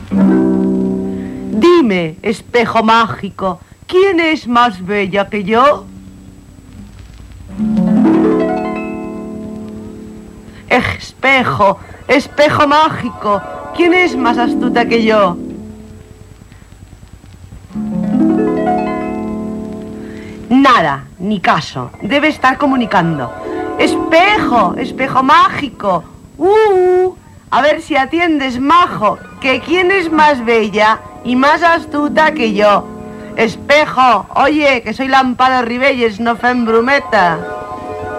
Maruja Torres parla al mirall màgic de "Blancaneus i els set nans".